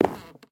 Sound / Minecraft / step / wood6.ogg
should be correct audio levels.
wood6.ogg